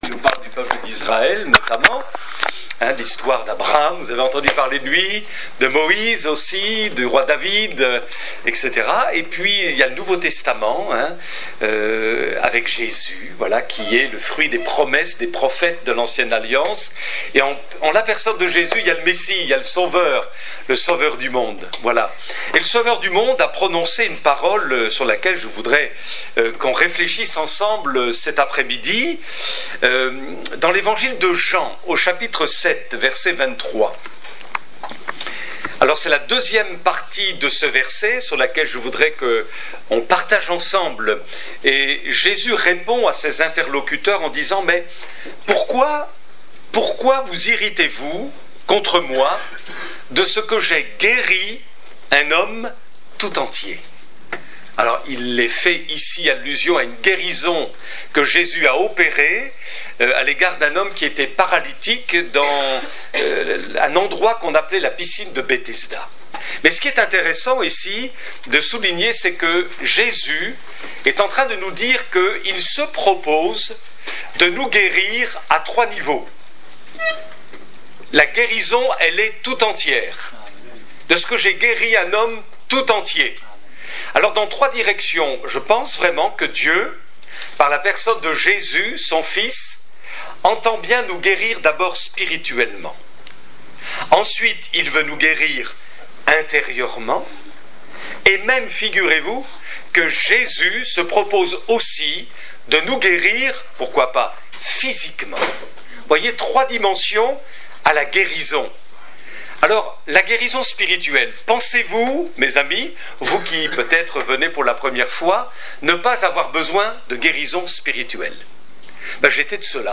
Service de baptêmes du 20 novembre 2016